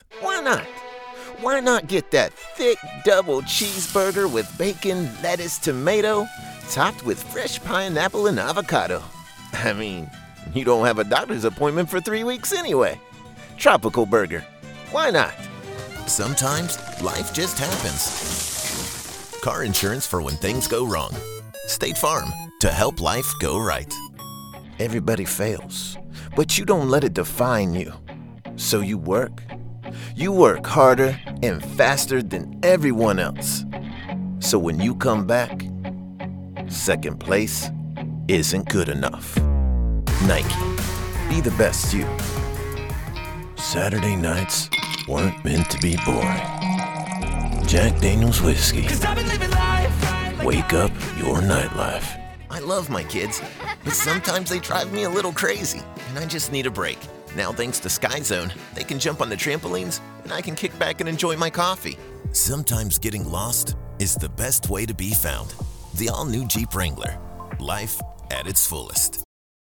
All Demos
Commercials, Animation, Video Games, Promos, Branding and more.